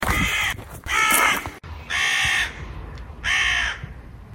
Écouter un des corbeaux qui vit à côté du cimetière